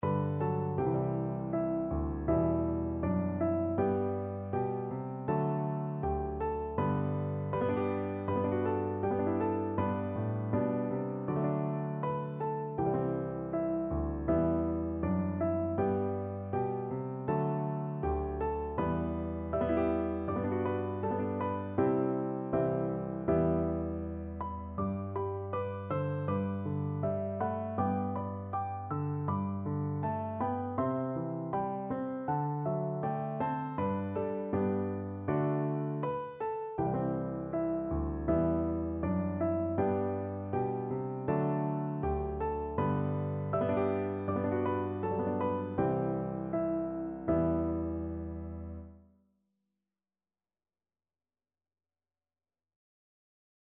Free Sheet music for Piano Four Hands (Piano Duet)
4/4 (View more 4/4 Music)
Andante Espressivo = c. 80
Traditional (View more Traditional Piano Duet Music)